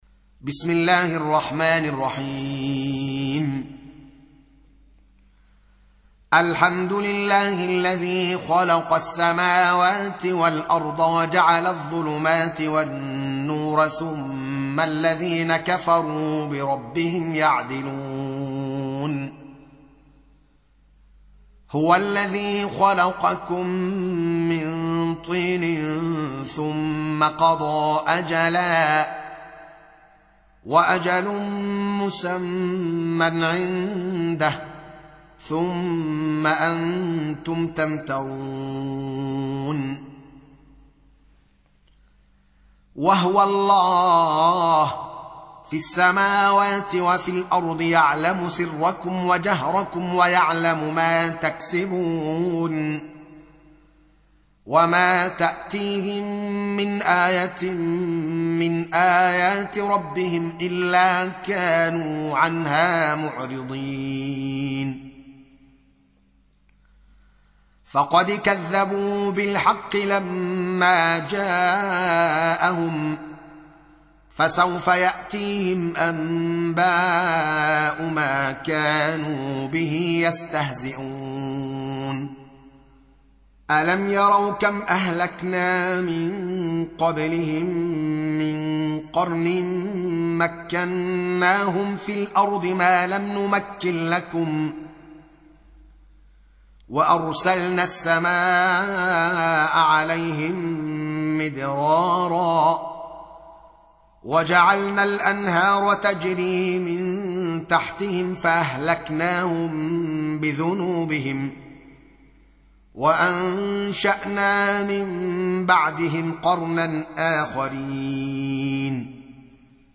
Surah Repeating تكرار السورة Download Surah حمّل السورة Reciting Murattalah Audio for 6.